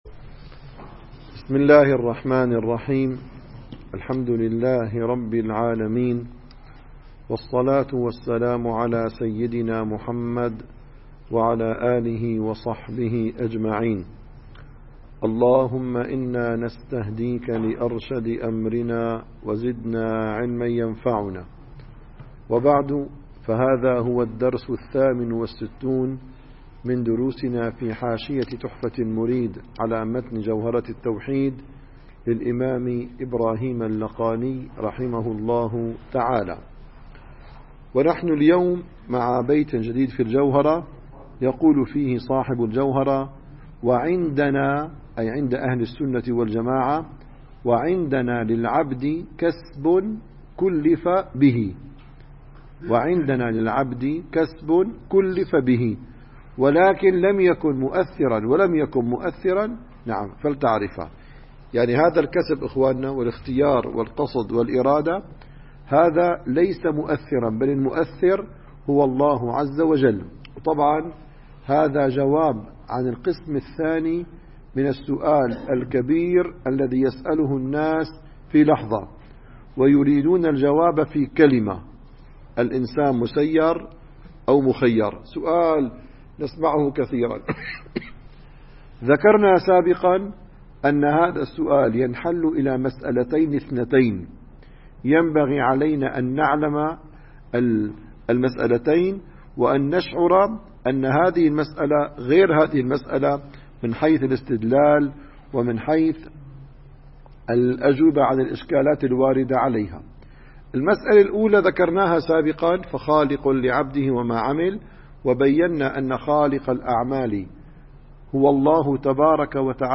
نسيم الشام › - الدروس العلمية - تحفة المريد - 68 بيان مذهب أهل السنة في أفعال العباد والرد على مخالفيهم